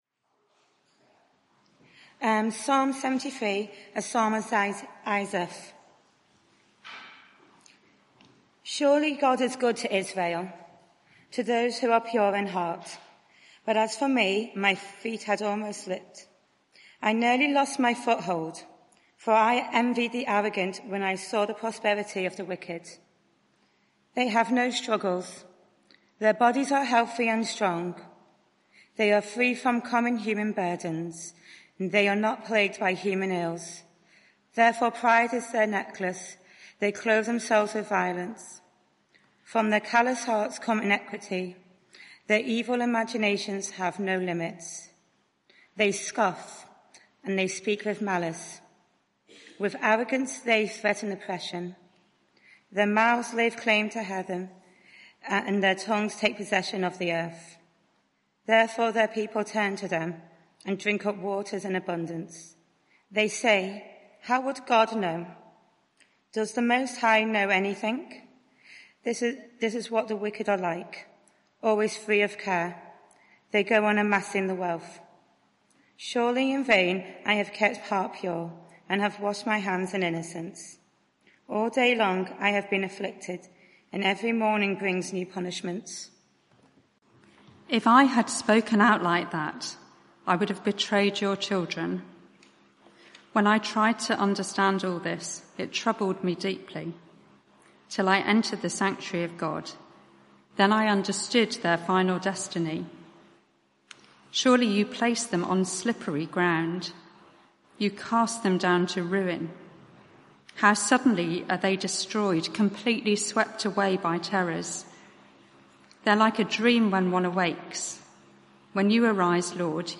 Media for 6:30pm Service on Sun 11th Aug 2024 18:30 Speaker
Series: Telling God How I Feel Theme: When I’m jealous Sermon (audio)